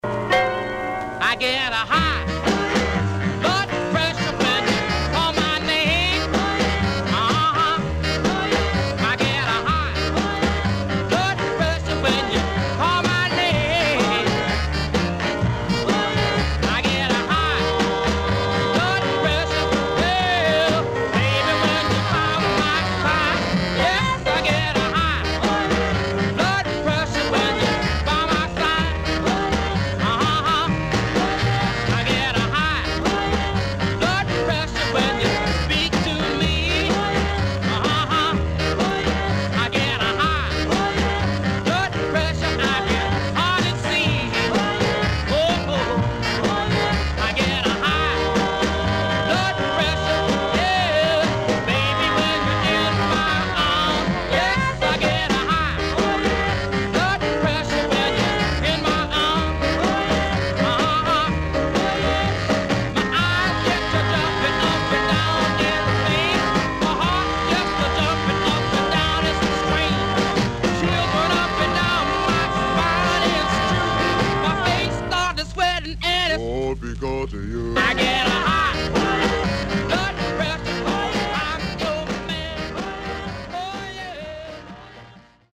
SIDE A:かるいヒスノイズ入りますが良好です。